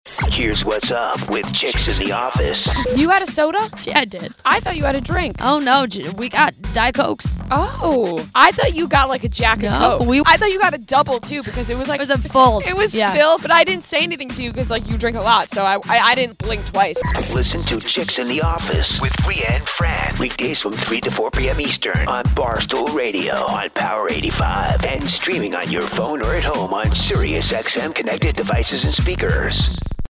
While working at SiriusXM on Barstool Radio, I sometimes put together 30-second spots that are played on other stations to promote the channel.
chicks-in-the-office-promo-diet-cokes.mp3